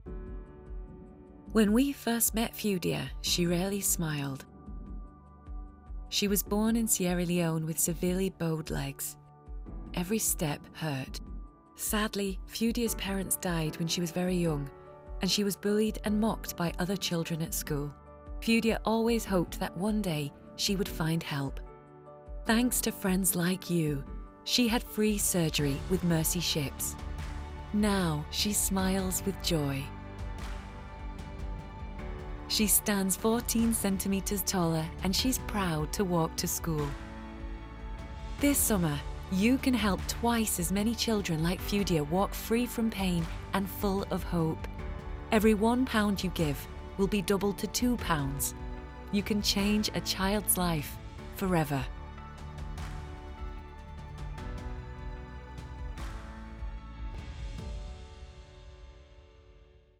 Soft, caring Northern accent - Charity Ad
Mercy Ships Ad - Audio .mp3